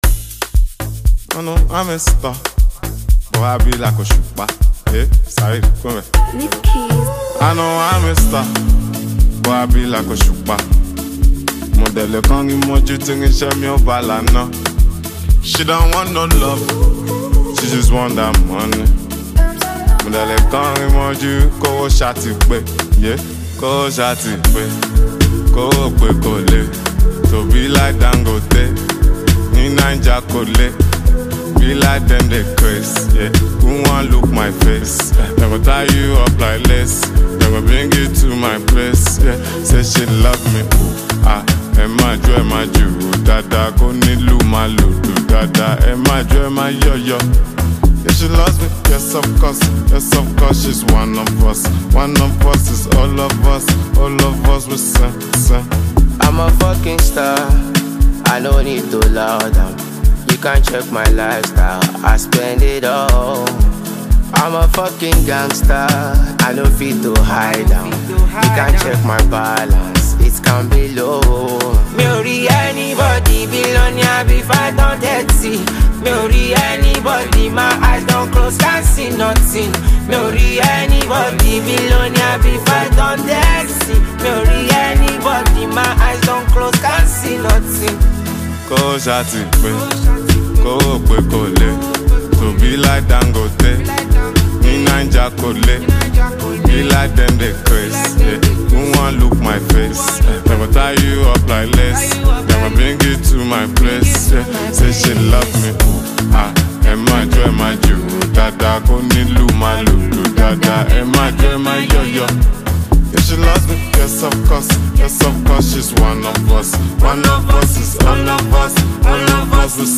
Afrobeat
With its upbeat tempo and catchy sounds